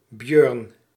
Dutch: [ˈbjʏr(ə)n]